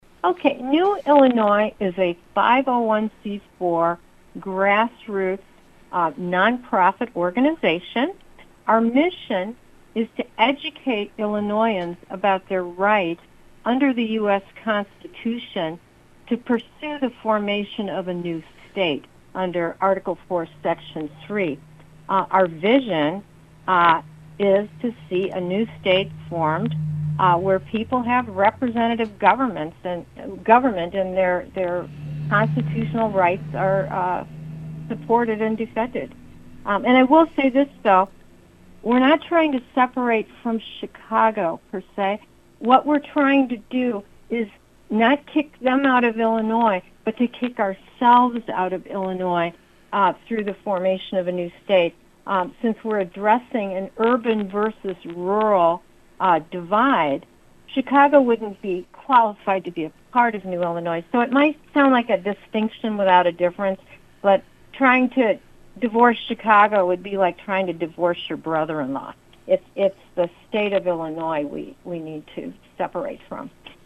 new-il-interview-part-1.mp3